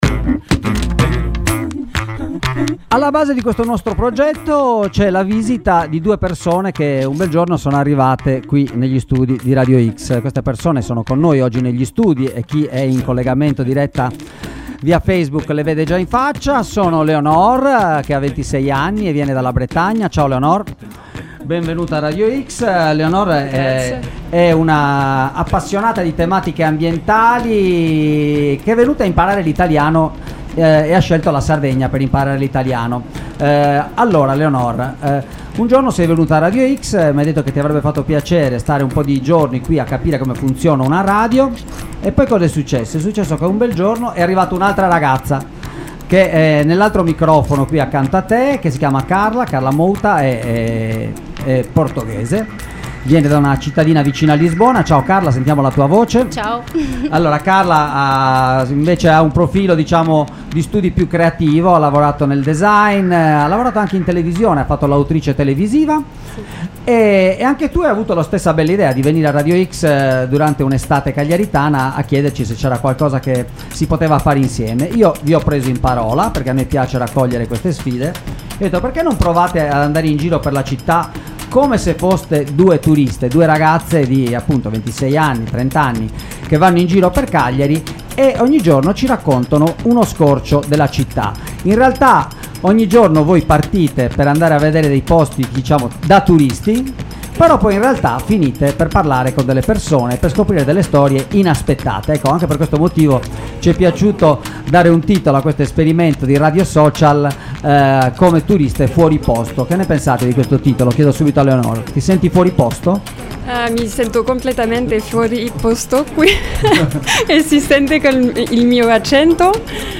Le ascolterete sui 96.8, dal lunedì al venerdì in diretta dalle 19: ci racconteranno i luoghi più caratteristici, le piazze, le spiagge, i mercati attraverso i loro occhi e attraverso le esperienze e le voci di chi abita la città.